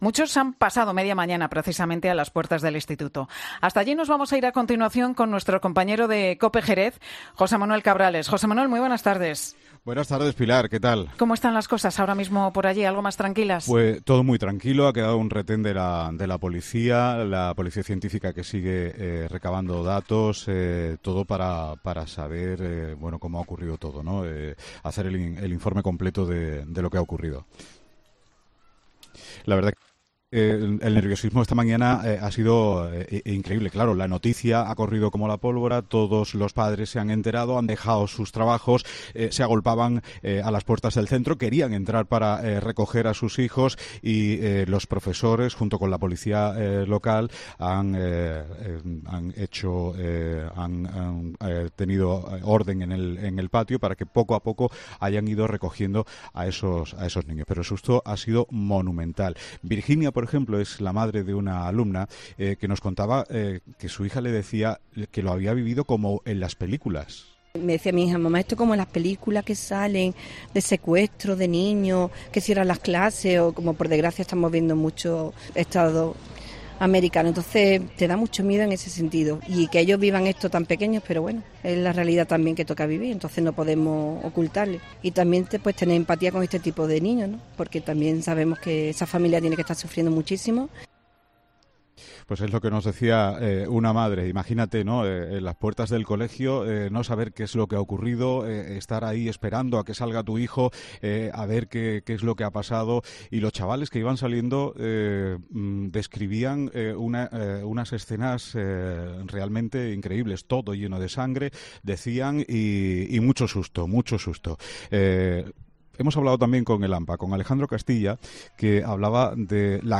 Varios testigos han relatado a COPE cómo han vivido los menores este ataque en el que un joven de 14 años ha sido detenido por apuñalar a varios alumnos y profesores